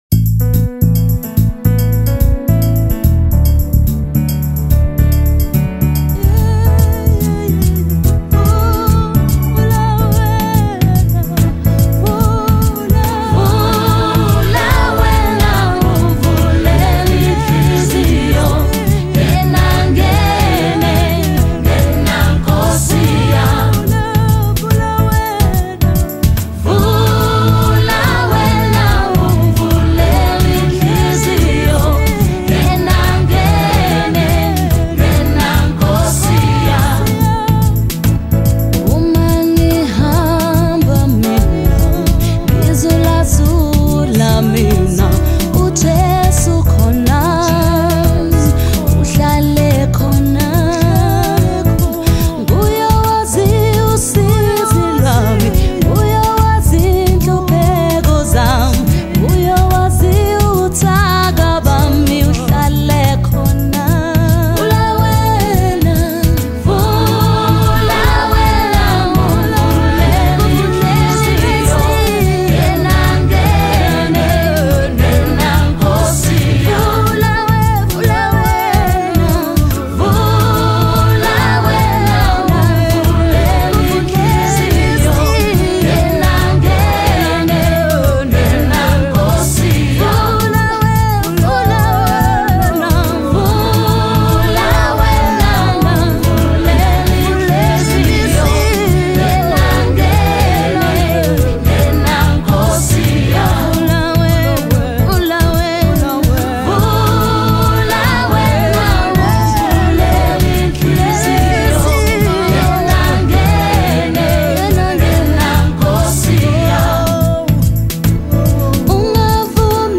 January 30, 2025 Publisher 01 Gospel 0